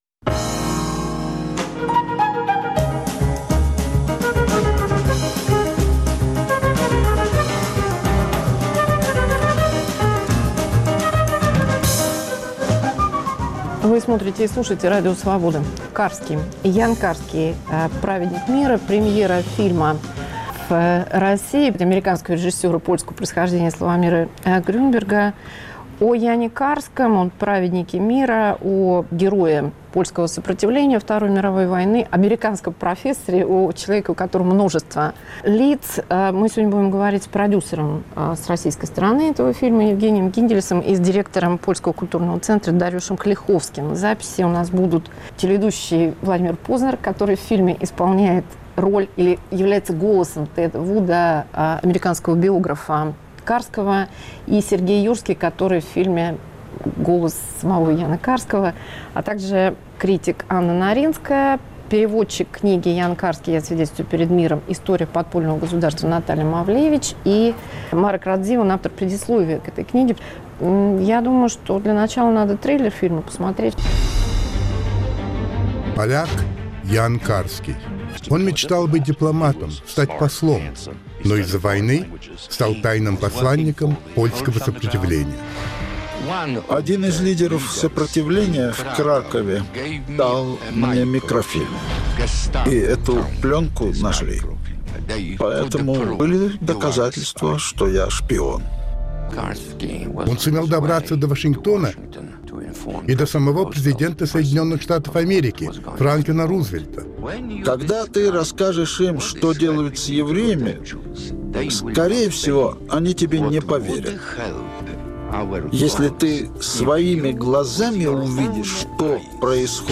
Владимир Познер, телеведущий; Сергей Юрский, актер;